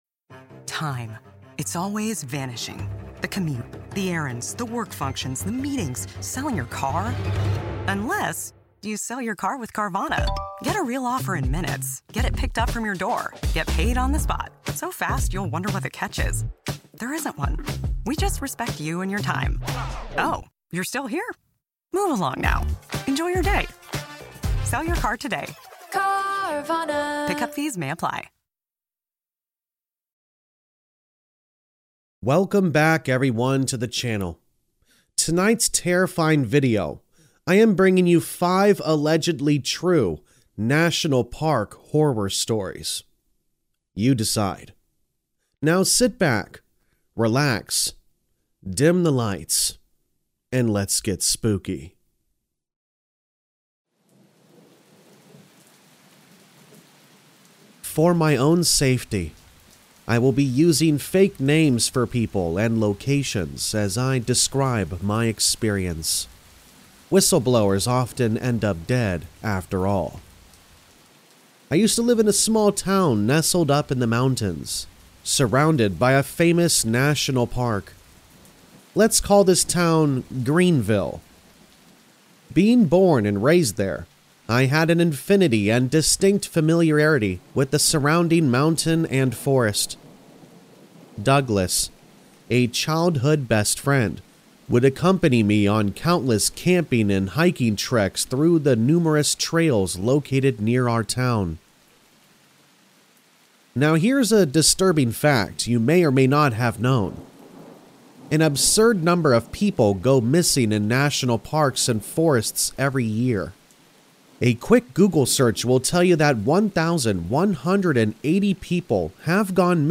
All Stories are read with full permission from the authors